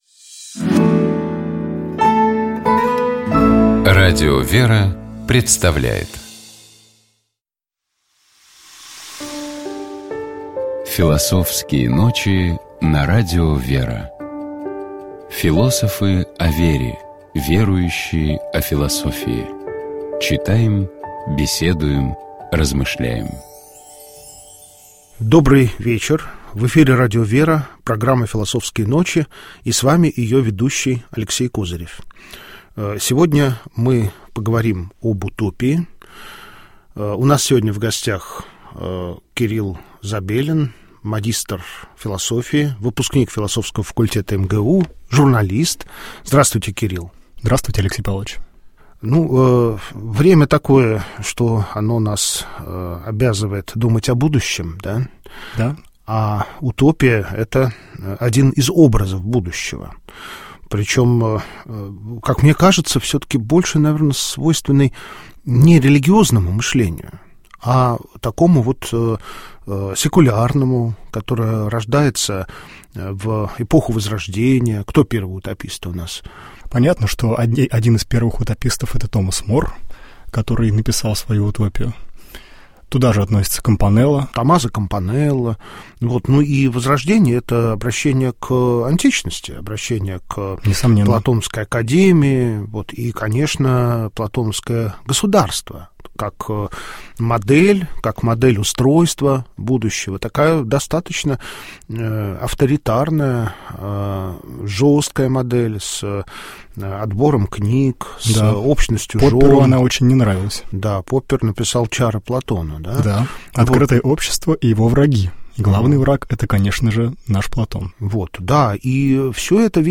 Гость программы